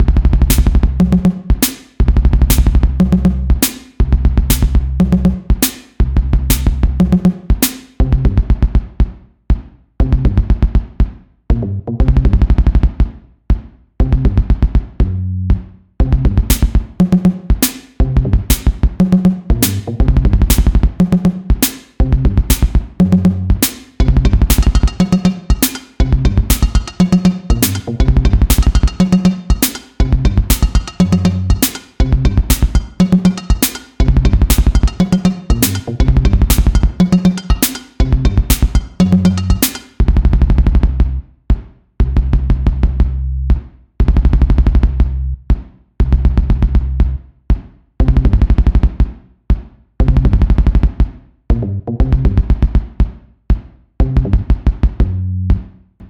Bucle de Electroclash
melodía
repetitivo
rítmico
sintetizador